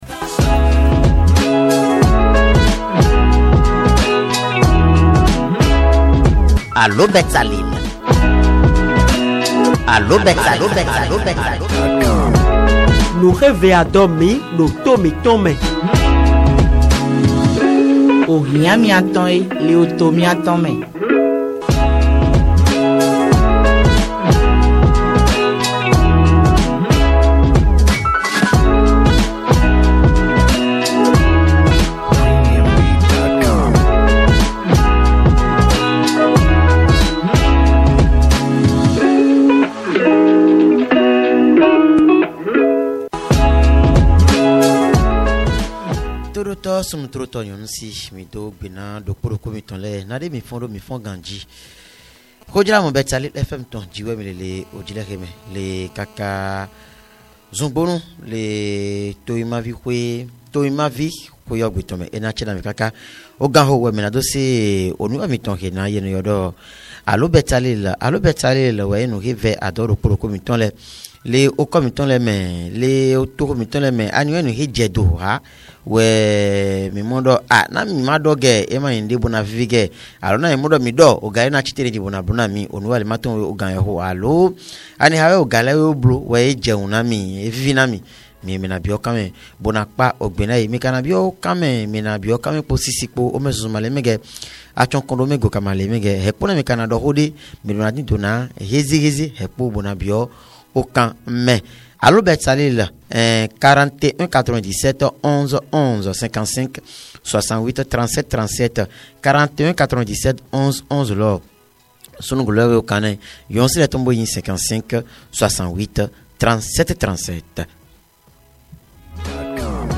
La dégradation des voies, le difficile accès de la population à l'eau potable, à l'électricité et aux soins de santé sont le lot quotidien des citoyens de nos communes. A micro ouvert, les auditeurs ont exprimé librement leur coup de gueule ce jeudi matin au cours de l'émission Allô Betsaleel